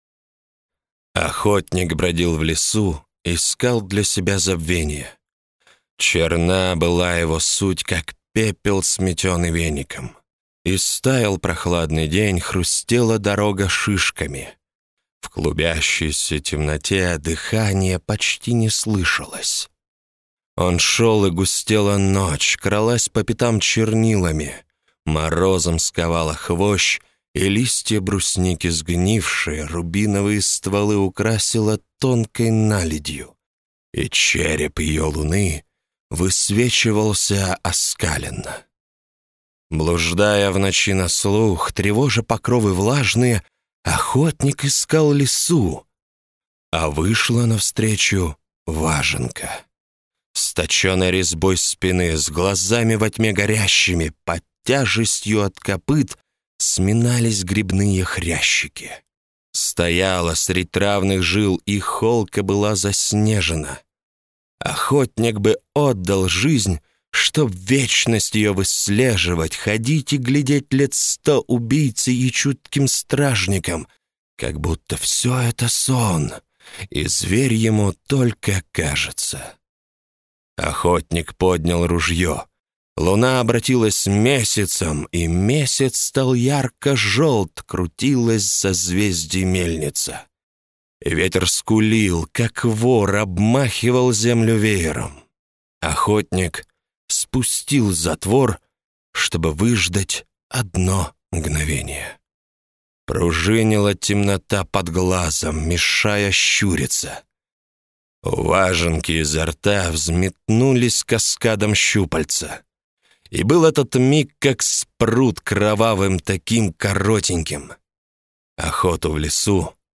Аудиокнига Рассказы 14. Потемки | Библиотека аудиокниг